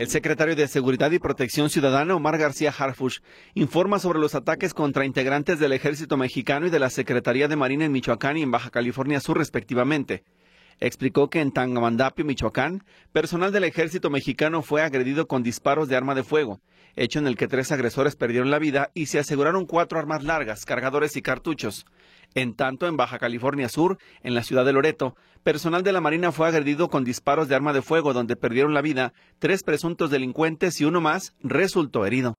El secretario de Seguridad y Protección Ciudadana, Omar García Harfuch, informa sobre los ataques contra integrantes del Ejército Mexicano y de la Secretaría de Marina en Michoacán y en Baja California Sur, respectivamente.